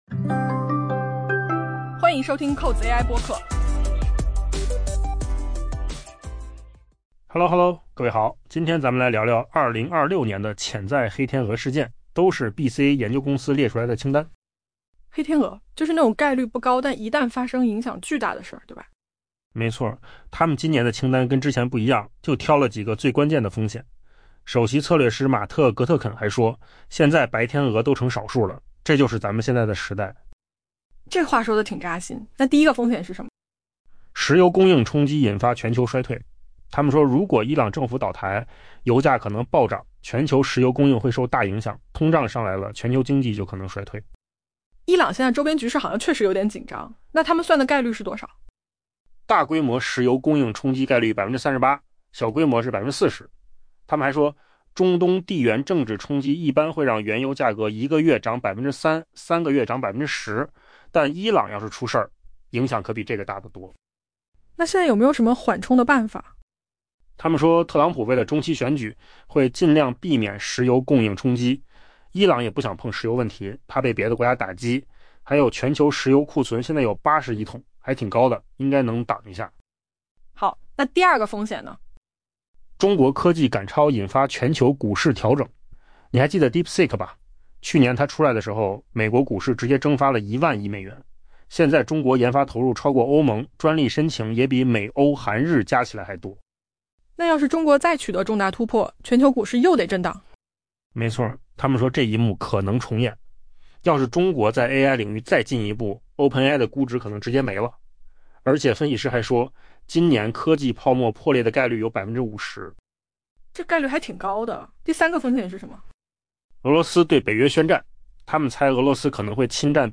AI 播客：换个方式听新闻 下载 mp3 音频由扣子空间生成 油价飙升全球经济陷入衰退、北约解体等事件，入选 BCA 研究公司列出的 2026 年潜在黑天鹅榜单。